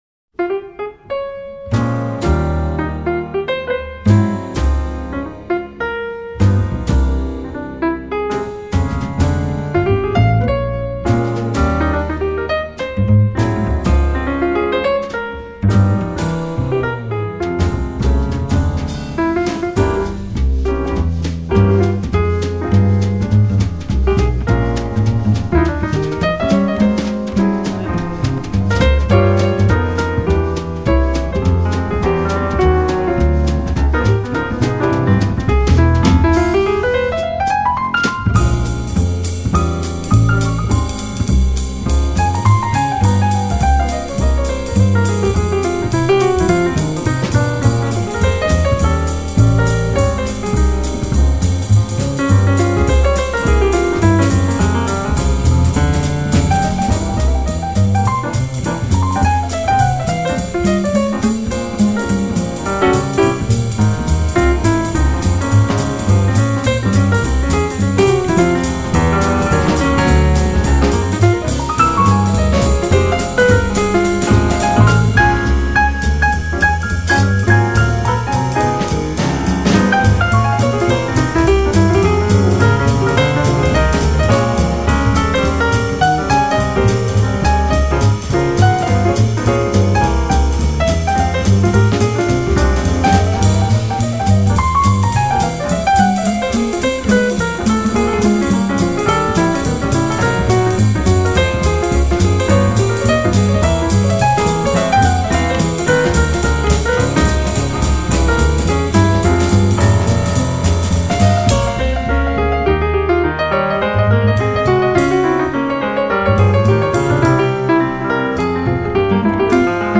的睿智与优雅，但是他的钢琴很流畅，很细腻， 是温柔而沉静的，你不需要
爵士鋼琴專輯